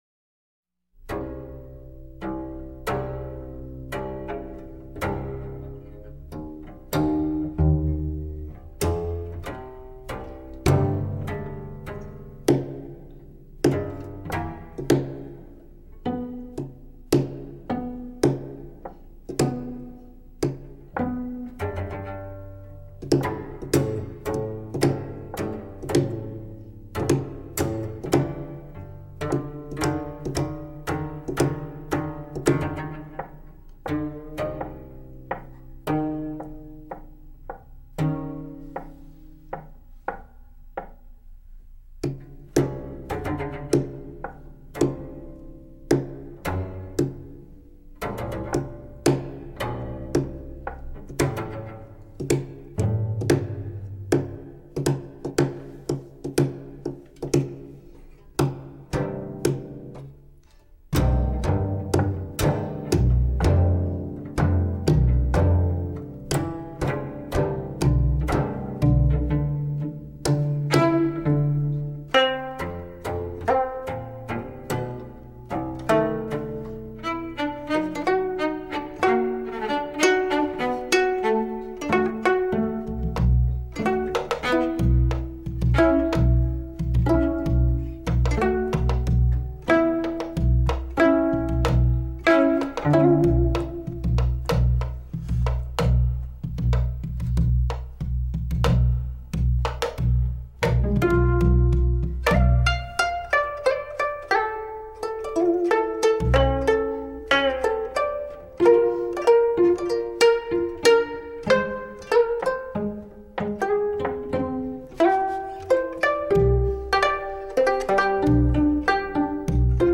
for changgu, kayagum, and cello
recorded in Seoul with: